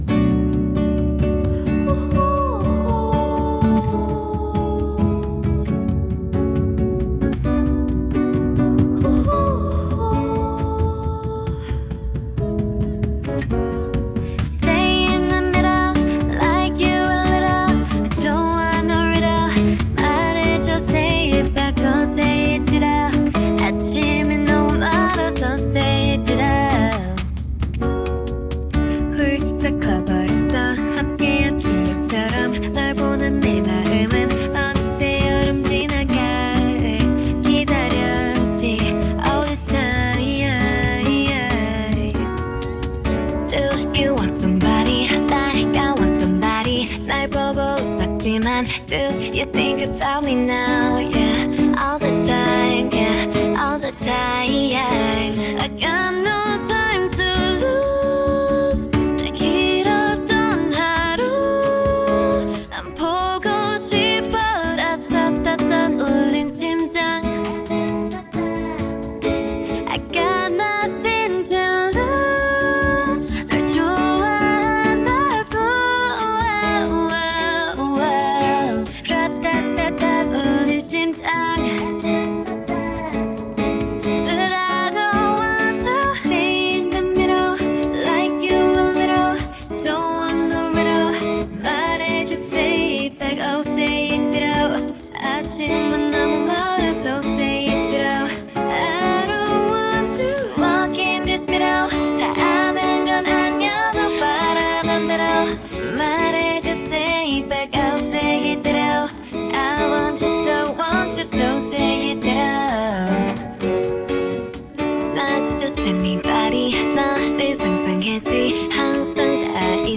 "bossa nova"